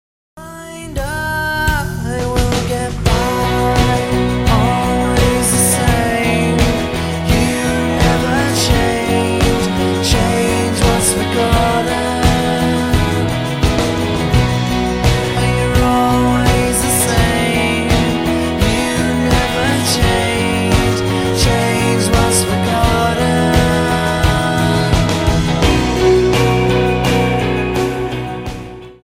A British band with an unusual type of sole music
Style: Roots/Acoustic